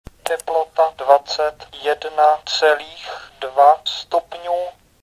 Univerzální mluvící zařízení
Zařízení je založeno na mikrořadiči a paměti EPROM obsahující namluvené vzorky hlasu (číslice, písmena a některá slova).